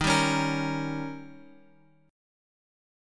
D#7 chord {x 6 5 6 x 6} chord
Dsharp-7th-Dsharp-x,6,5,6,x,6.m4a